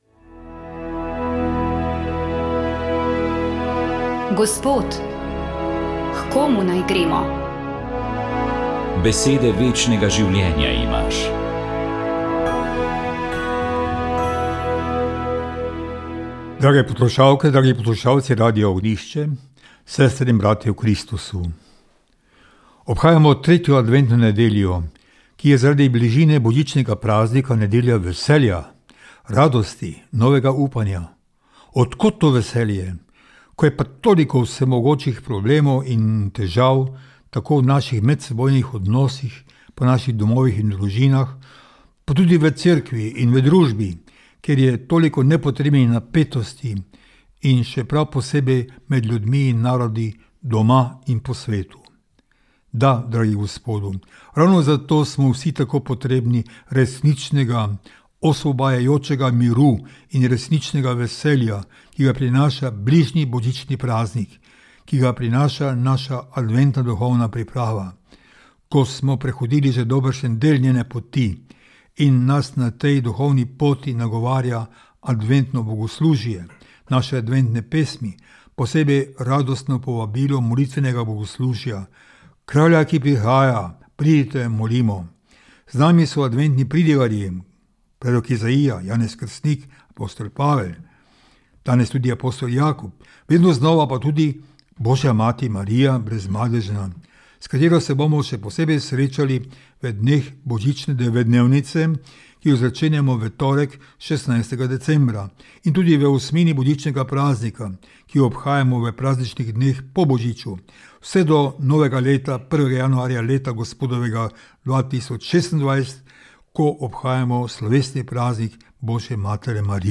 Duhovni nagovor
Duhovni nagovor je pripravil ljubljanski pomožni škof Anton Jamnik.